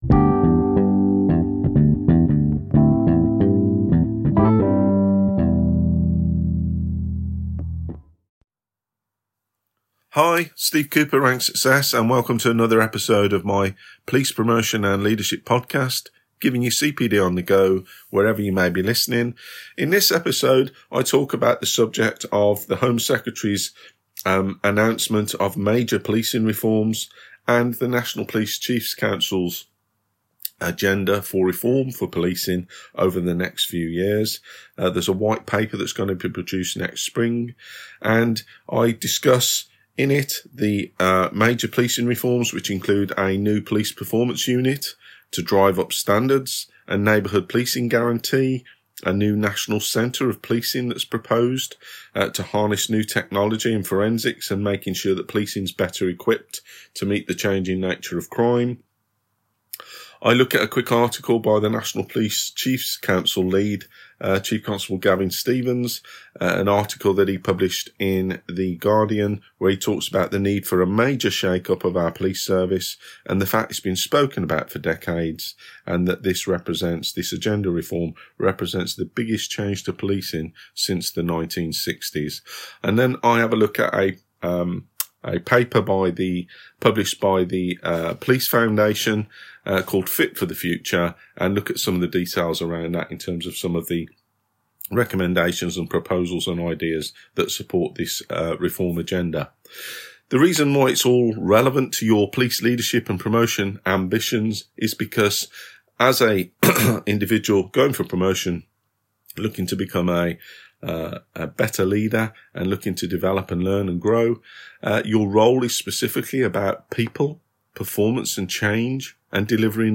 Please excuse the cold I had recording this!